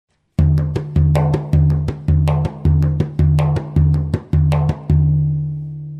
Bodhran Notations
If you leave out the last two strokes of the pattern, you are in 6/8: